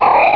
Cri de Gloupti dans Pokémon Rubis et Saphir.